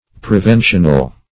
Preventional \Pre*ven"tion*al\